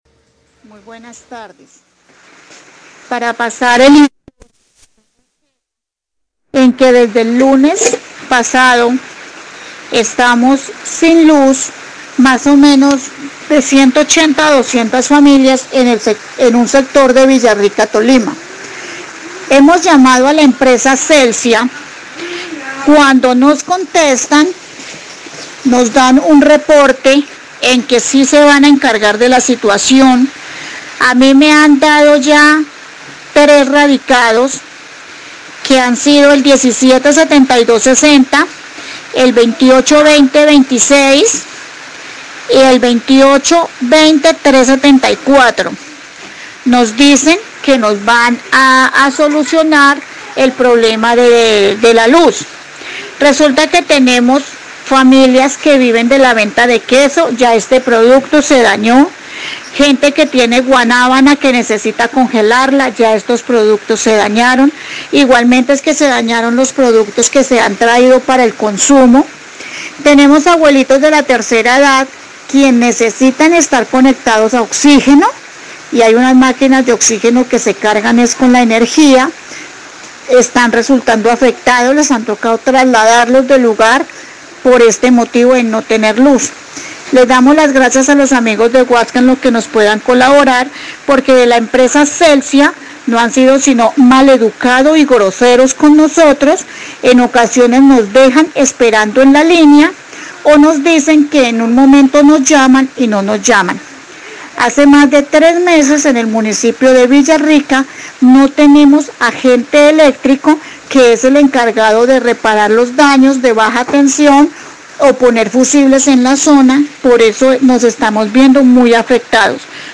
Radio
Oyente denuncia que más de 200 familias en Villarica Tolima se encuentran sin energía, esto ha generado daños en alimentos que necesitan refrigeración, además los adultos mayores se han visto afectados gravemente al depender del oxígeno  y por tal motivo han tenido que trasladarlos a otros lugares.